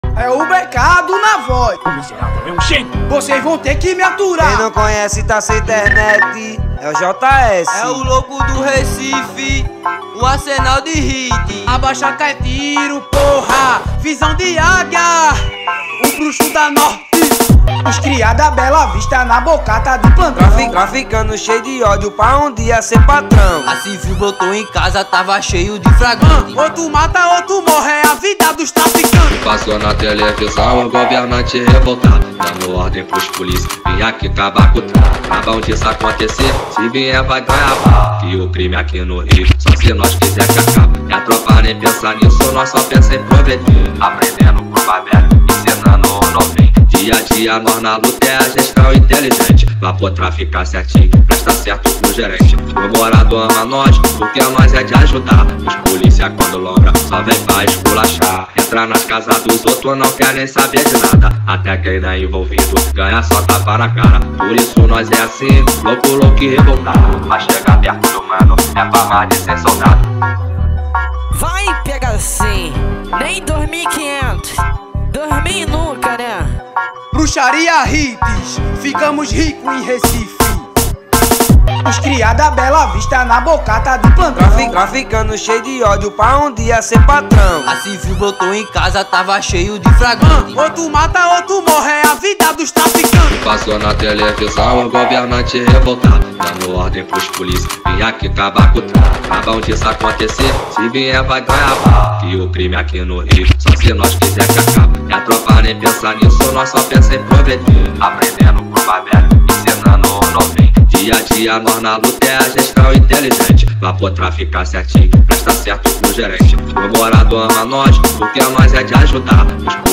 2025-01-28 14:53:49 Gênero: MPB Views